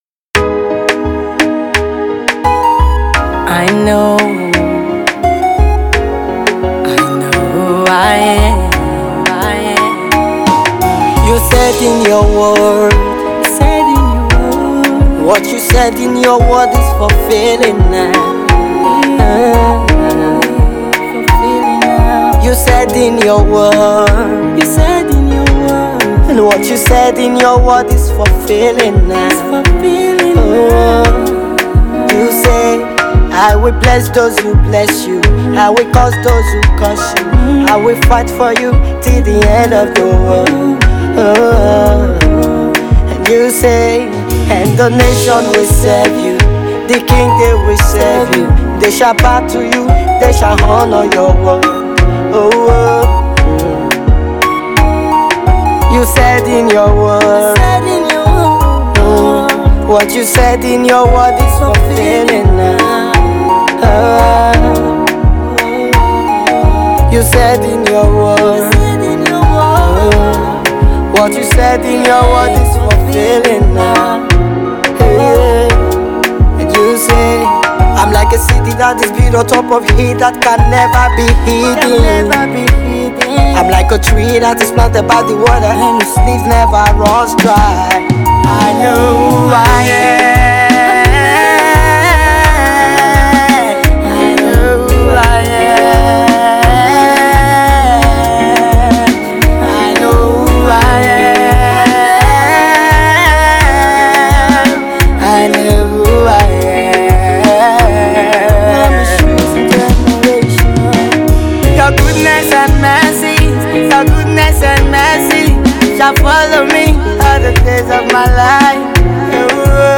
power-packed song